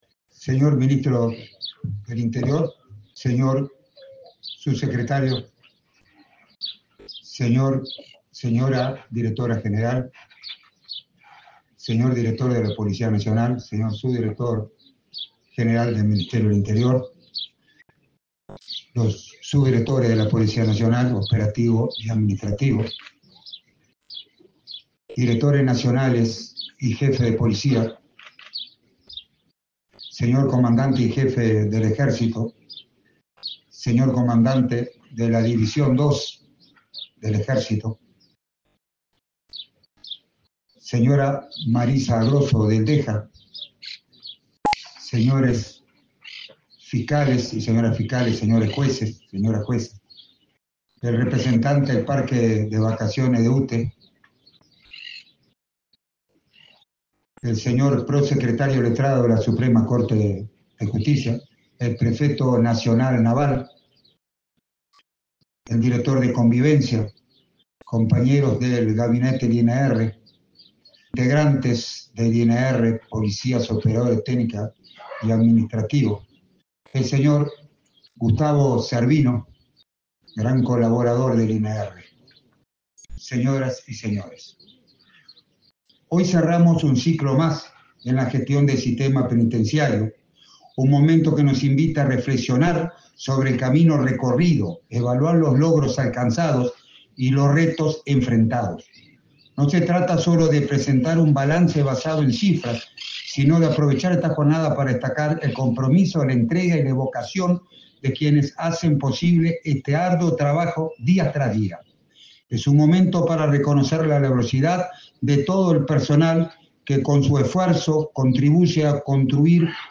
Palabras del director del INR, Luis Mendoza
El Instituto Nacional de Rehabilitación (INR) celebró su 14.° aniversario, en la unidad n.° 2 de San José, este 16 de diciembre.